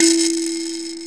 SANZA 3 E3.WAV